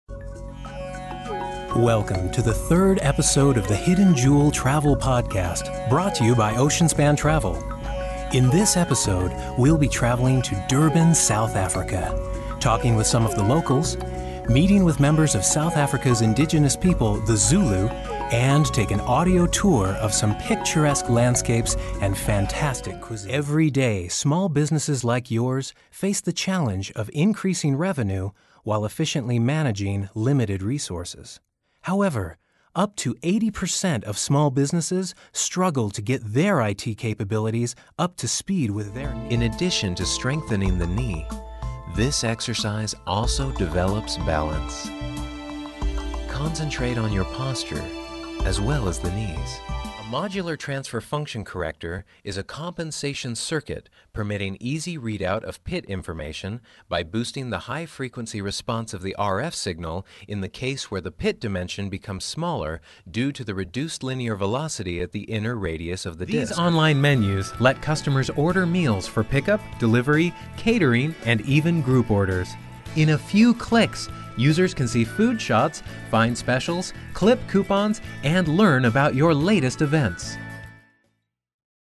Male
English (North American)
Adult (30-50)
Narration